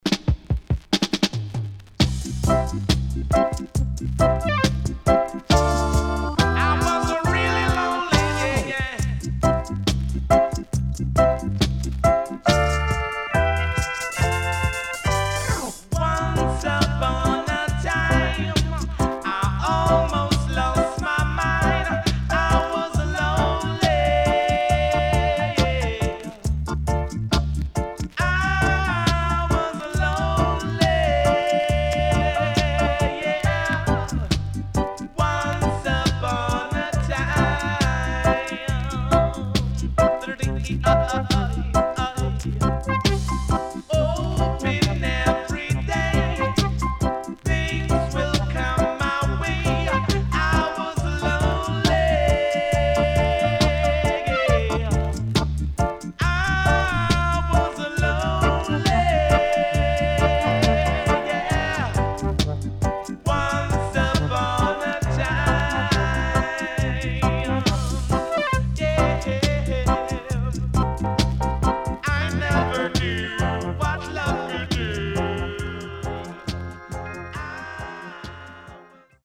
SIDE B:プレス起因で少しチリノイズ入ります。